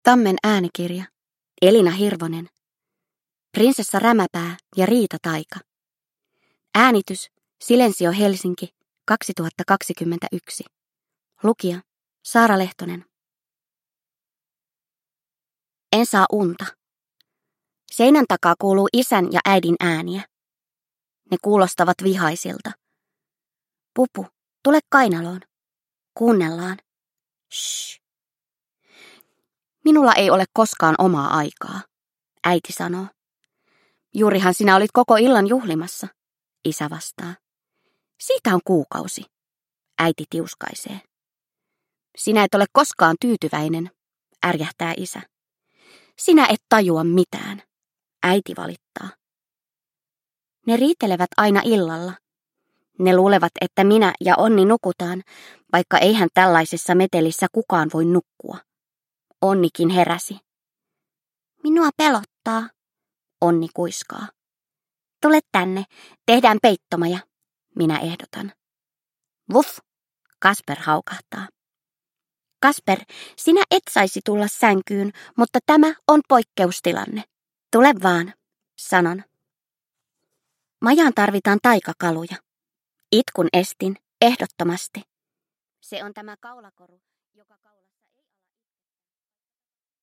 Prinsessa Rämäpää ja riitataika – Ljudbok – Laddas ner